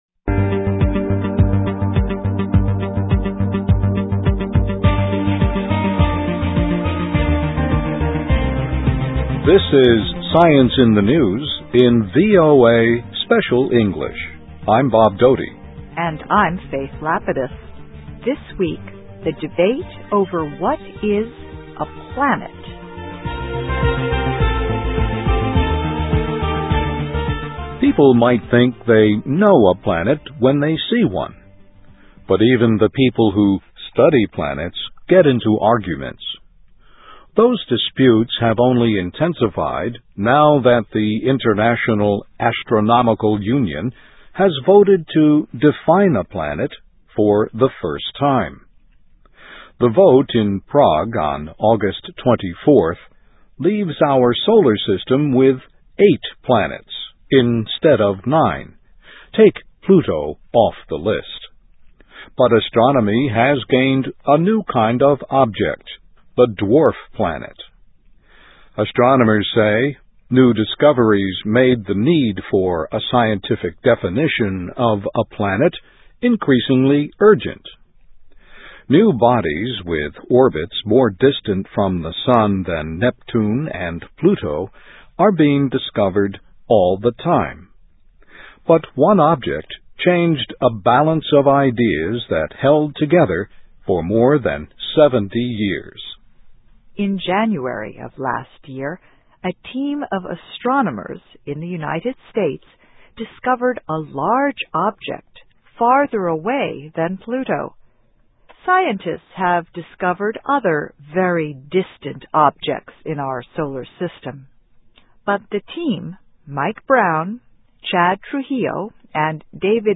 ESL, EFL, English Listening Practice, Reading Practice